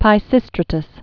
(pī-sĭstrə-təs, pĭ-)